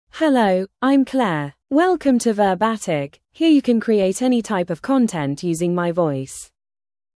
FemaleEnglish (United Kingdom)
Claire is a female AI voice for English (United Kingdom).
Voice sample
Female
Claire delivers clear pronunciation with authentic United Kingdom English intonation, making your content sound professionally produced.